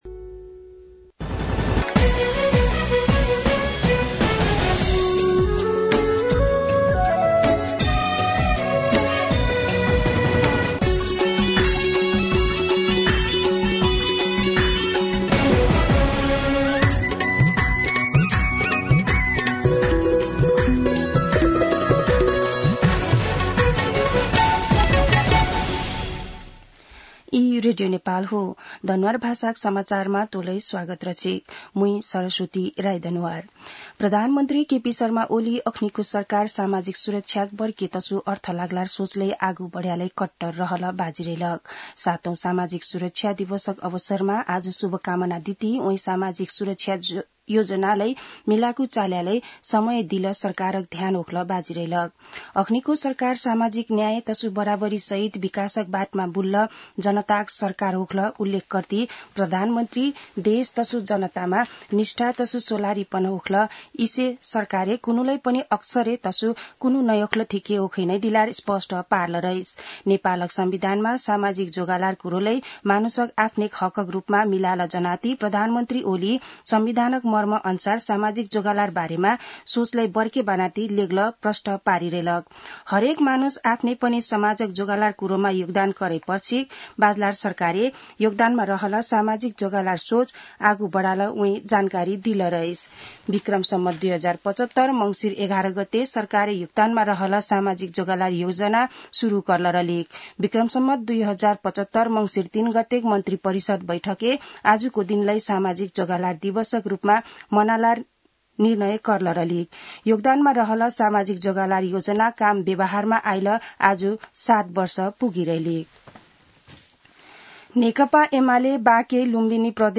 दनुवार भाषामा समाचार : १२ मंसिर , २०८१